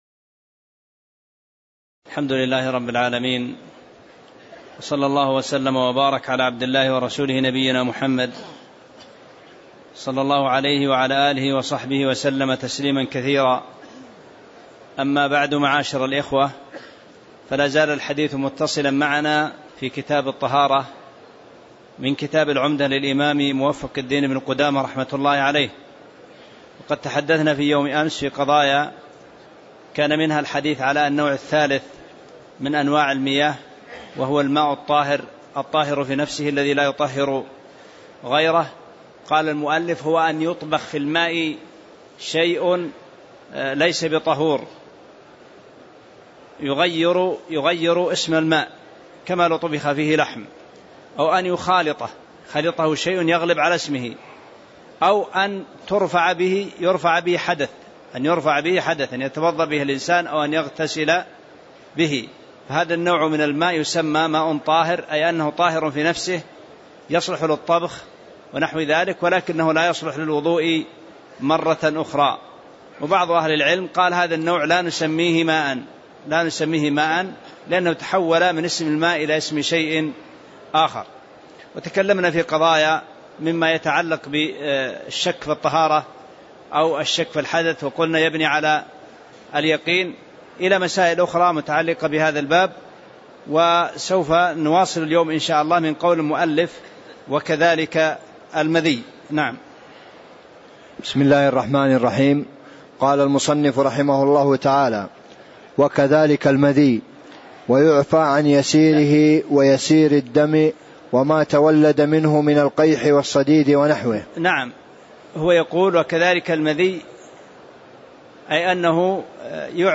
تاريخ النشر ٢٧ ذو الحجة ١٤٣٧ هـ المكان: المسجد النبوي الشيخ